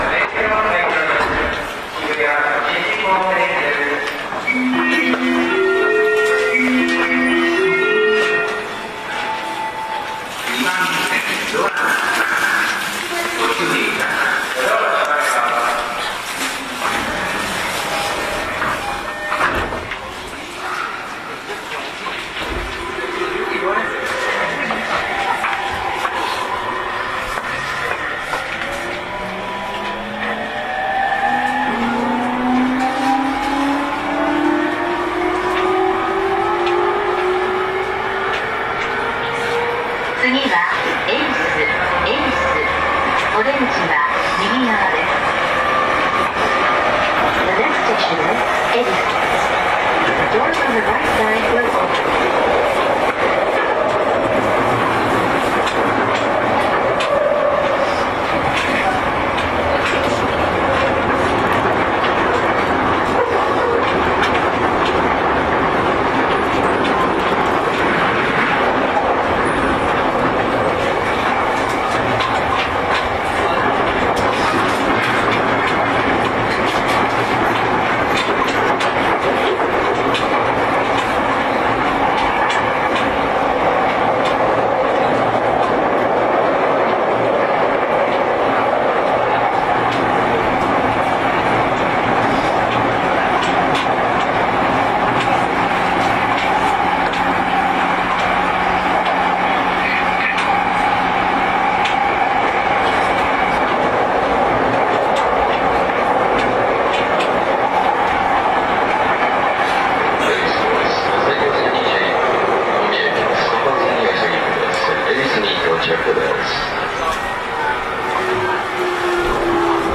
走行音
YM11 E231系 目黒-恵比寿 2:23 8/10 上の続きです。